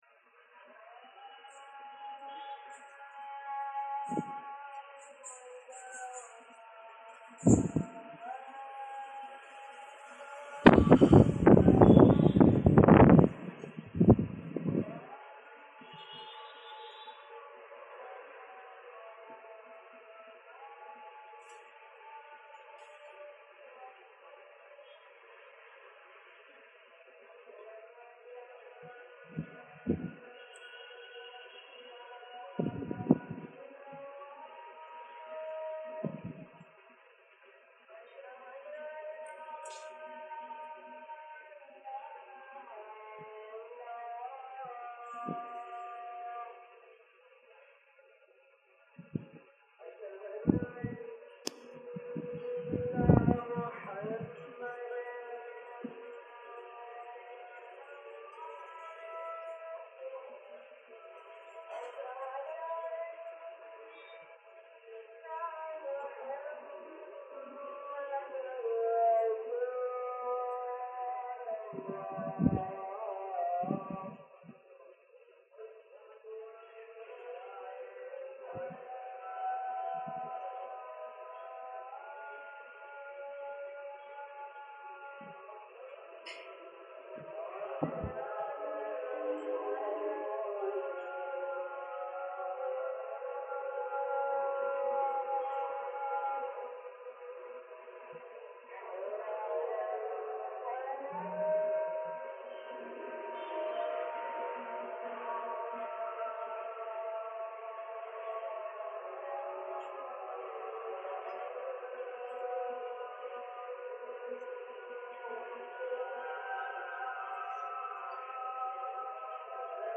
Resultatet blev följande ljudfil, som jag har filtrerat för både låga och höga frekvenser för att försöka få bort så mycket vind- och trafikljud som möjligt (jodå, det är en hel del trafik även 4.45 på morgonen):
Det är lite svårt att höra ordentligt på inspelningen, jag vet. Jag använde telefonen och den är inte riktigt byggd för att spela in på det här sättet. Likväl så är det en ganska fascinerande och inte helt otrevlig ljudmatta som breder ut sig över landskapet i mörkret innan gryningen (lägg märke till att solen inte går upp förrän efter 5, så det är fortfarande mörkt när detta utspelar sig).